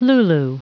Prononciation du mot lulu en anglais (fichier audio)
Prononciation du mot : lulu